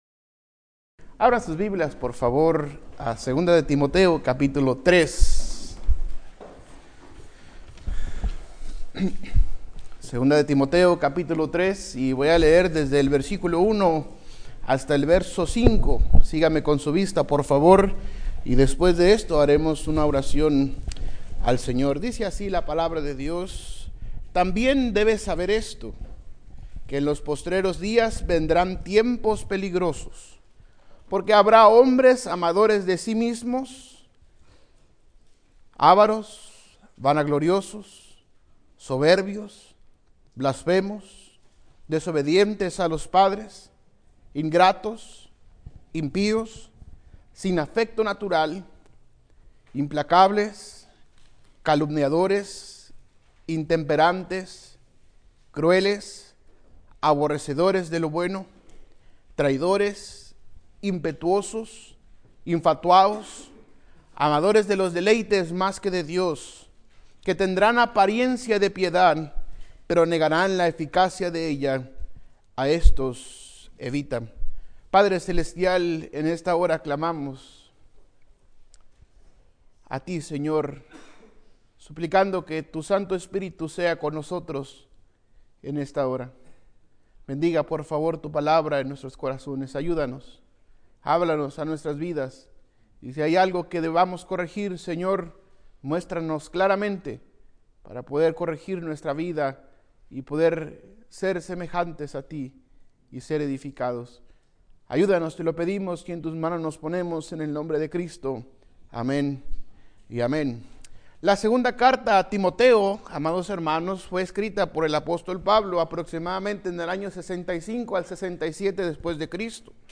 Servicio Matutino